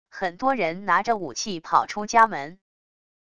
很多人拿着武器跑出家门wav音频